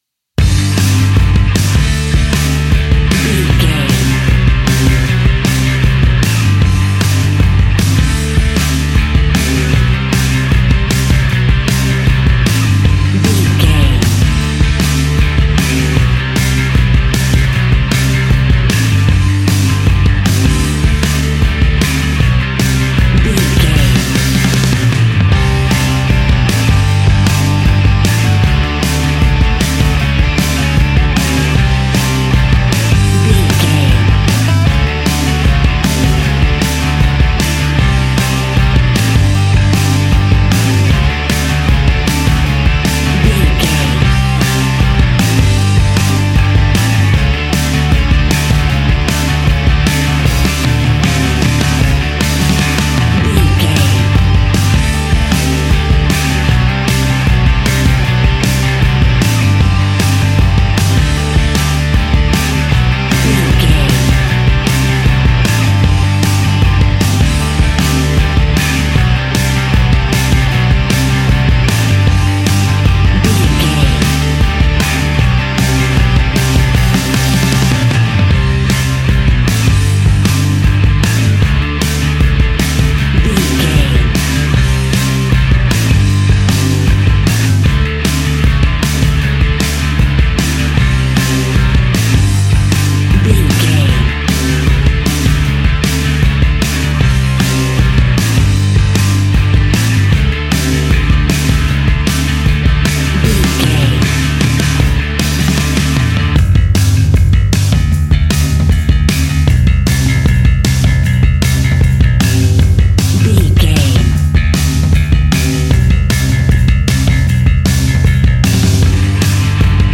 Epic / Action
Fast paced
Ionian/Major
hard rock
punk metal
Rock Bass
heavy drums
distorted guitars
hammond organ